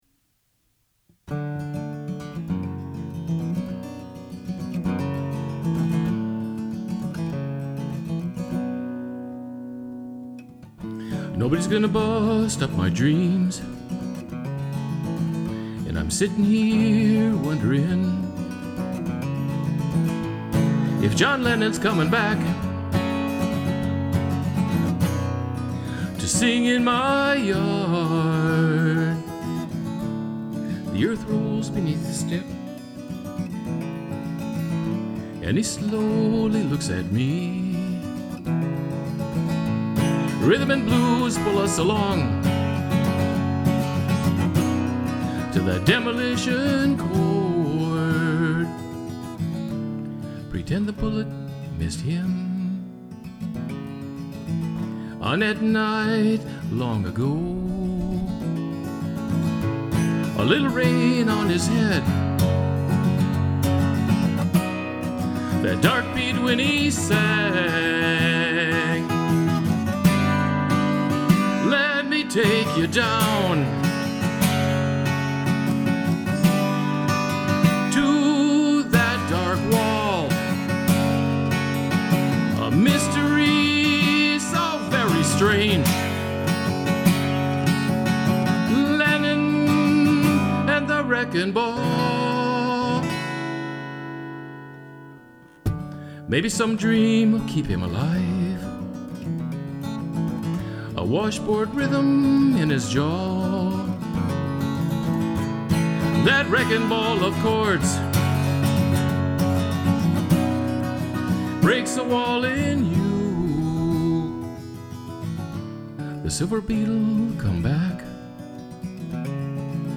guitar, vocal
recorded & mixed at Gummersound